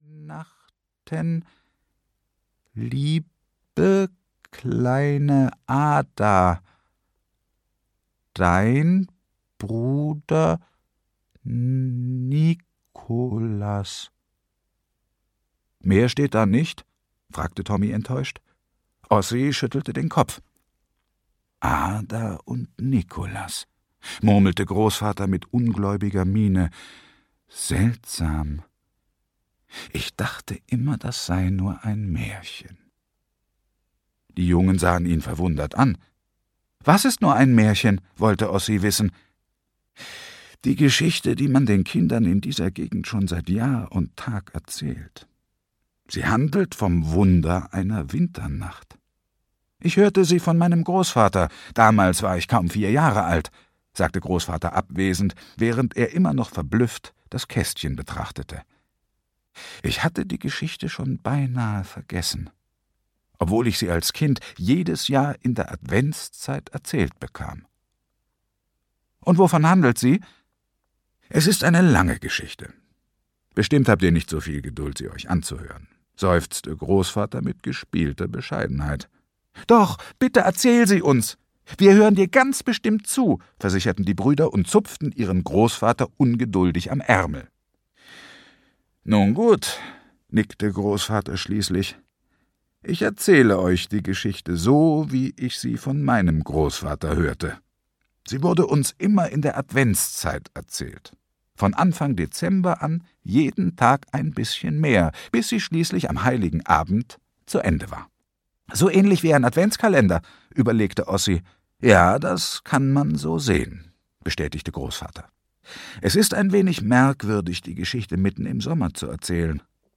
Wunder einer Winternacht. Die Weihnachtsgeschichte - Marko Leino - Hörbuch